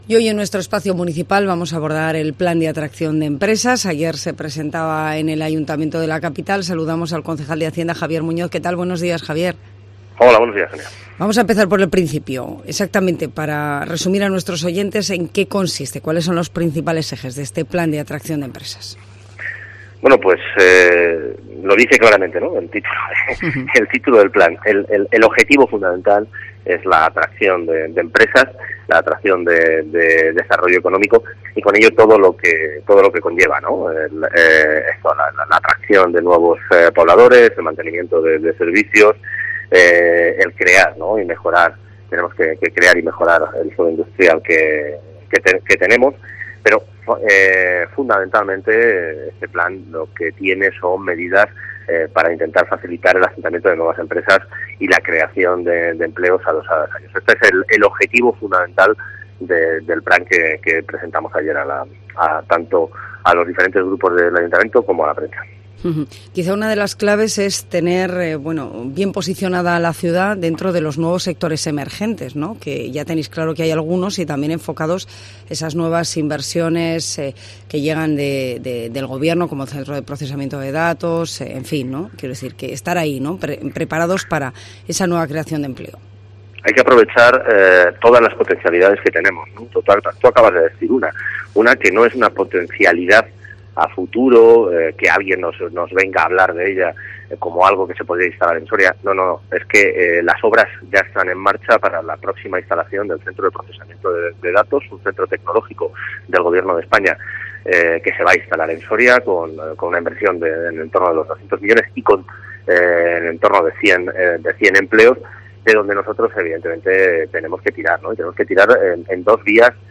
AUDIO: Javier Muñoz, concejal de Hacienda de Soria, cuenta en COPE las claves del Plan de Atracción de Empresas